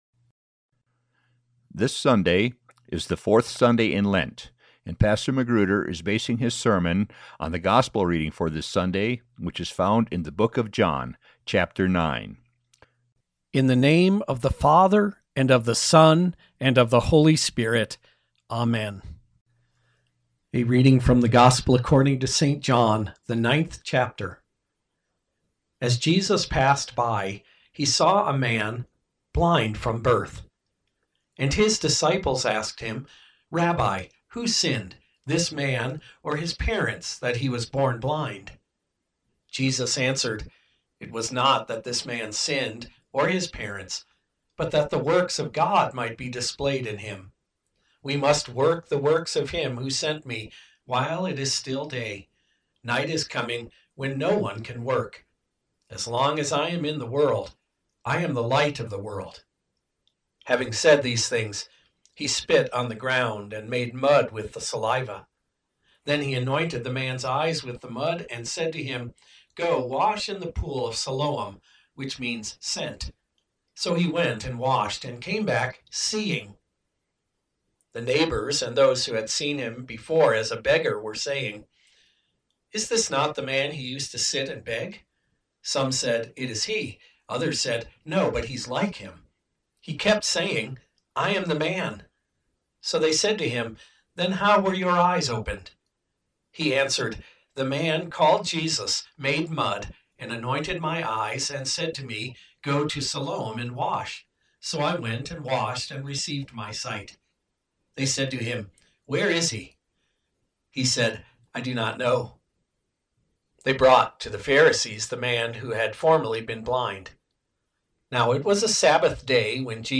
Seeing - Sermon At Peace With Christ Lutheran Church